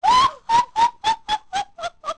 • Although Kotake and Koume are identical twins, aside from their respective elements of fire and ice, their laughs are
OOT_Twinrova_Kotake_Laugh.wav